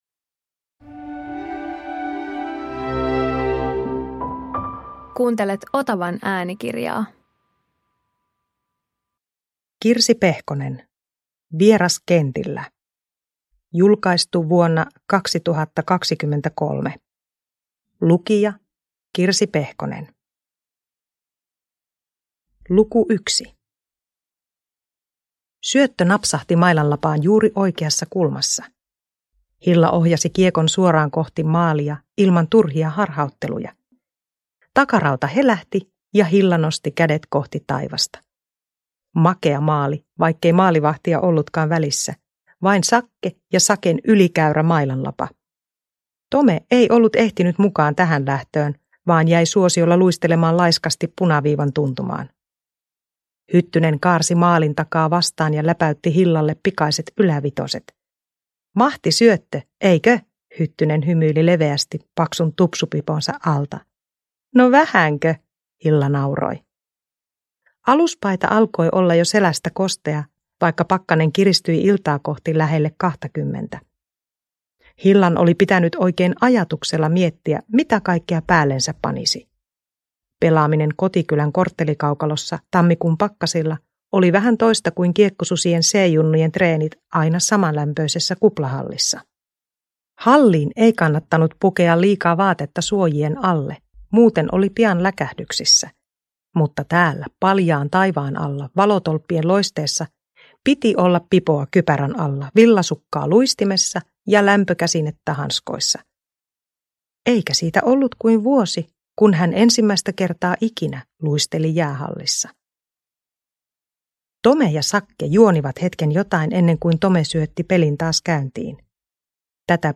Vieraskentillä – Ljudbok – Laddas ner